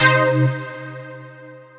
Synth Stab 14 (C).wav